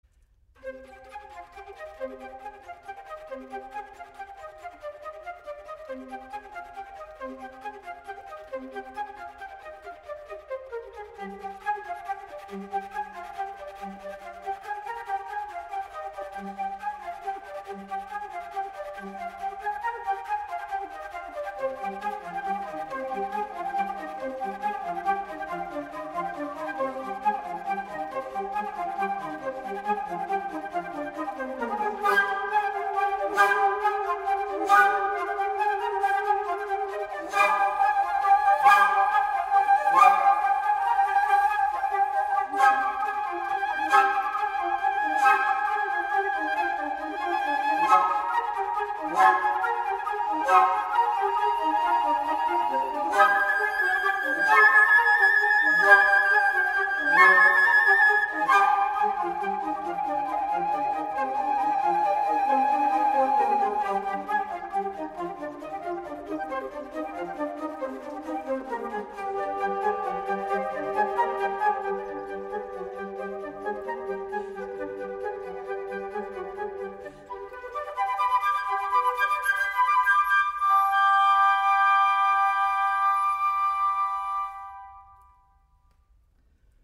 Ausführende sind acht Frankfurter Musikerinnen, die alle ihre musikalisch-künstlerische Ausbildung an einer europäischen Musikhochschule absolviert haben.
Ort: Bessunger Kirche
Leyenda-Albeniz-Floetenspektakel.mp3